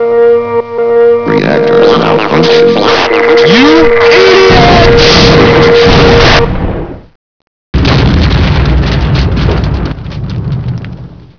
reactormalfunction.wav